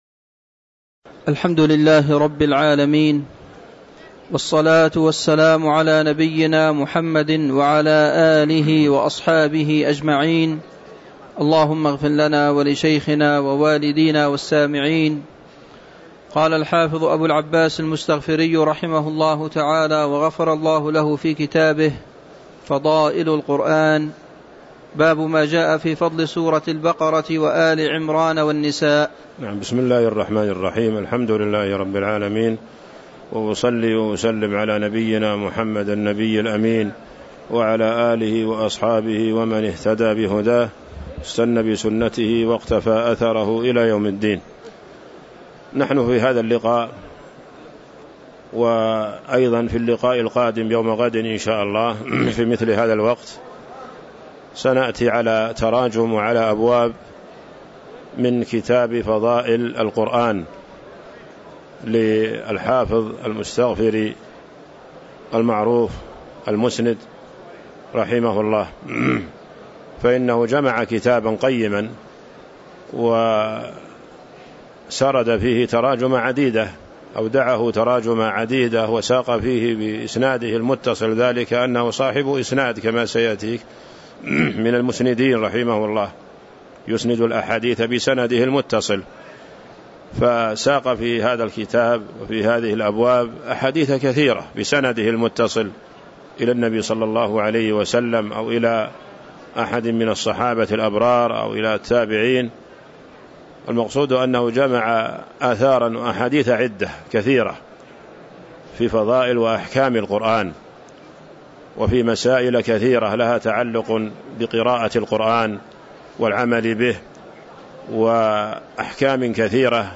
تاريخ النشر ٣ رمضان ١٤٤٥ هـ المكان: المسجد النبوي الشيخ